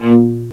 CELLO1.WAV